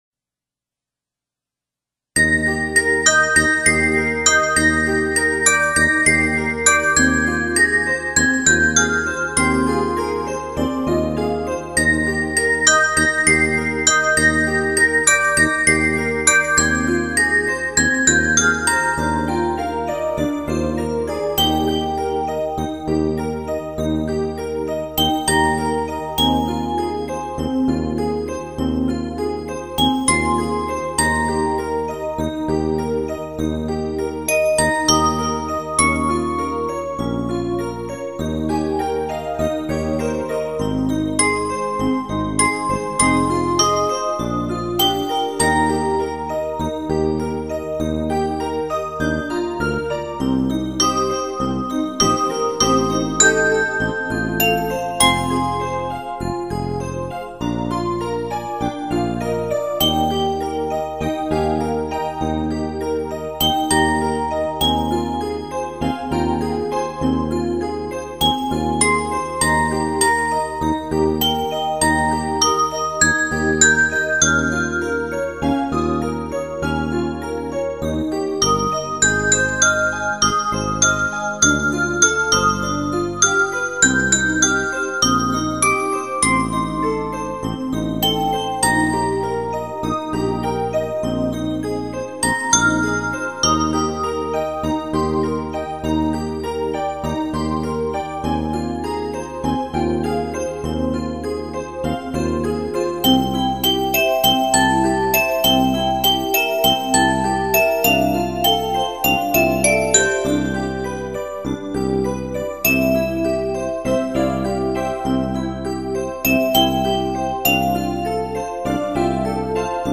晶莹剔透的舒畅感，更是令人心醉，
水晶音乐，带给您无限遐思......